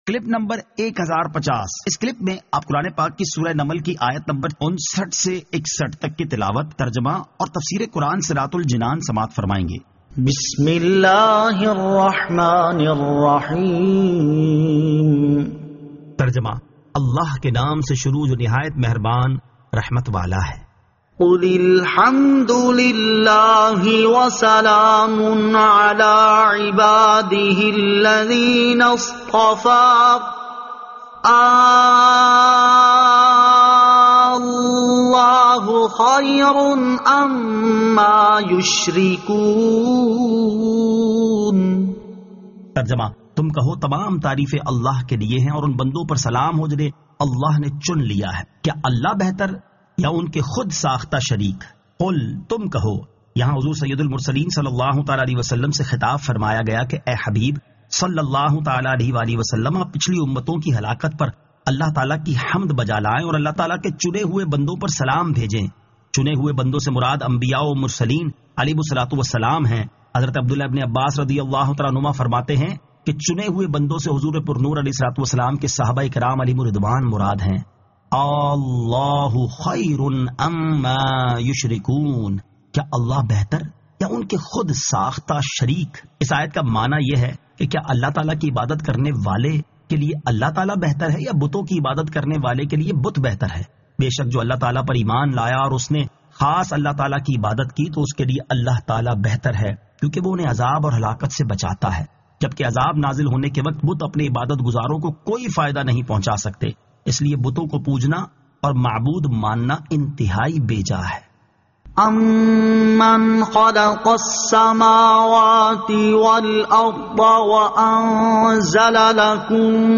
Surah An-Naml 59 To 61 Tilawat , Tarjama , Tafseer